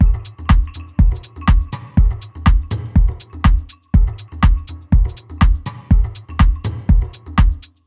• house - techno beat passage 122bpm - Em - 122.wav
A loop that can help you boost your production workflow, nicely arranged electronic percussion, ready to utilize and royalty free.